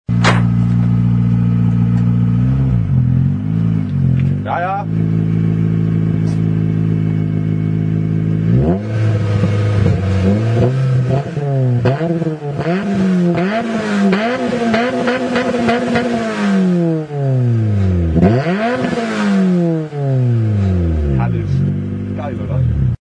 Megan Racing N1 Muffler 2,5"
• Geschwärzter, herausnehmbarer doppelt geschichteter Silencer.
• Durch die zweifache Schalldämpfung wird die Lautstärke bis zu 30% verringert.
Suzuki Swift Gti
n1muffler.mp3